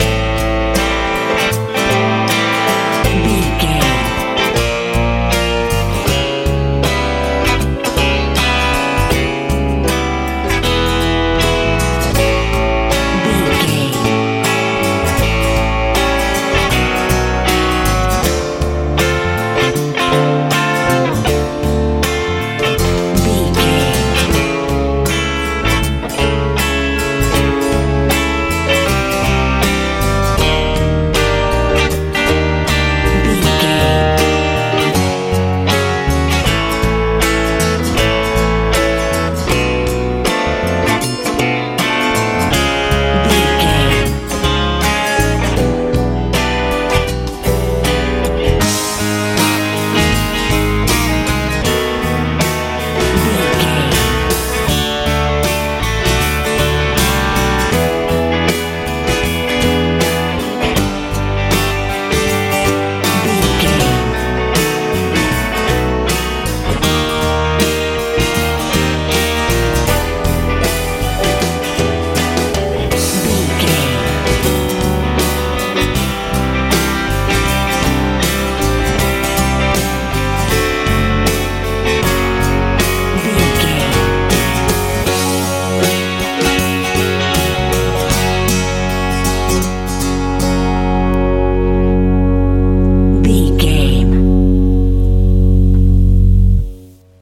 easy rock
Aeolian/Minor
soothing
sweet
piano
electric guitar
acoustic guitar
bass guitar
drums
tranquil
smooth
relaxed
confident
calm
melancholy